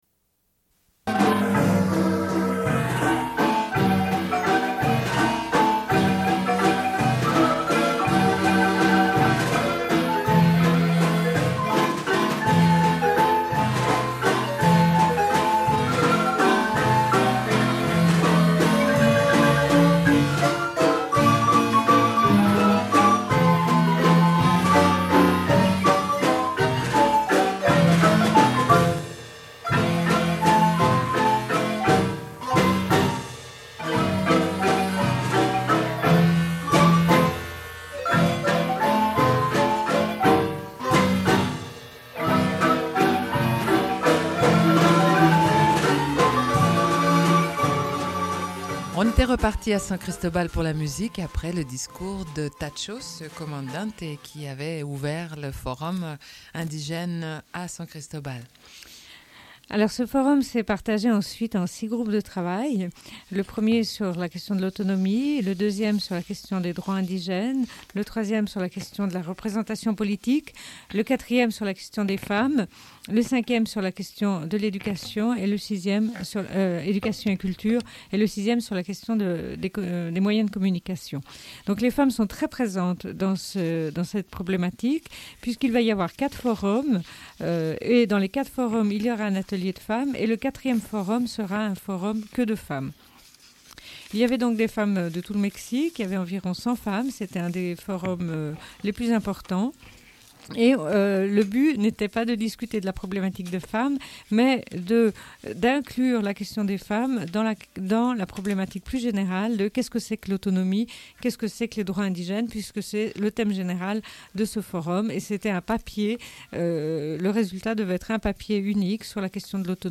Une cassette audio, face A
Radio Enregistrement sonore